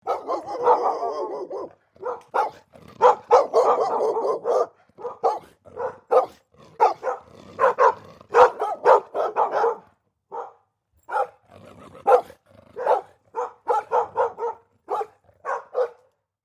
Несколько собак лают на чужого пса (звук стаи)